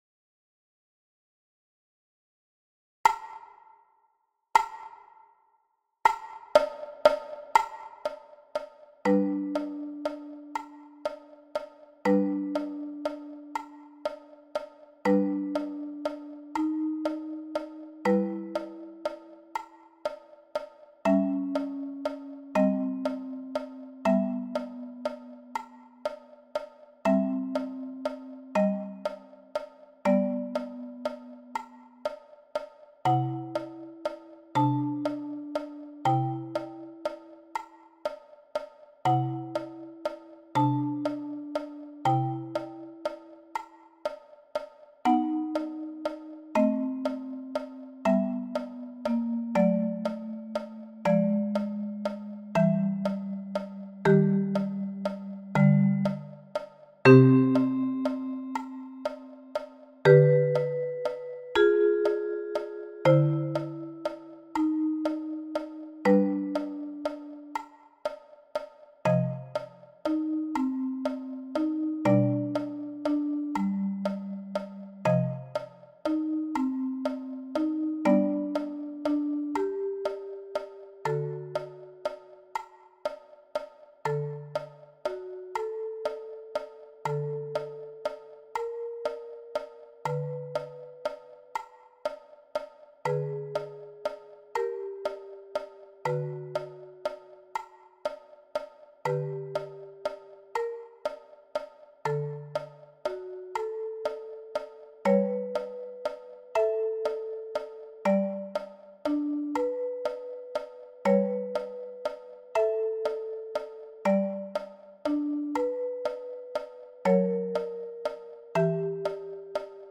Sheherazade – prince et princesse – accompagnement – 40 bpm